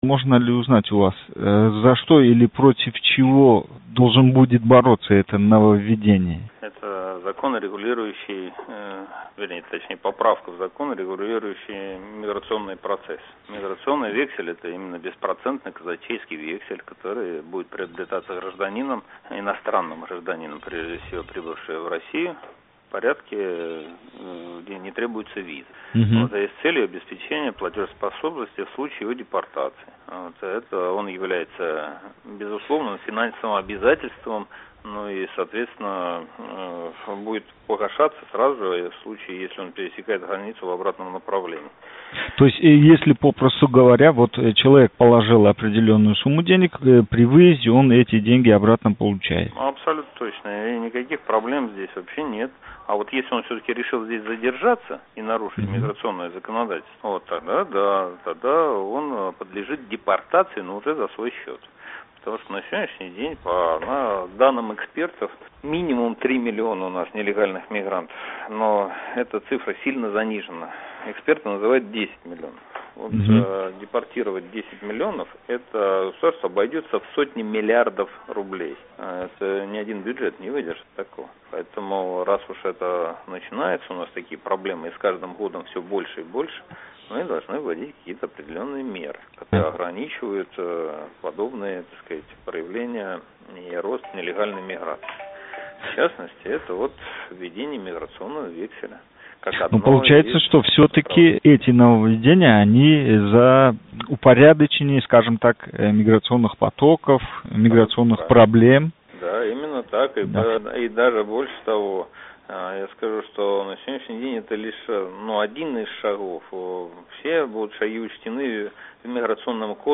Россия Давлат думаси депутати Журавлев билан суҳбат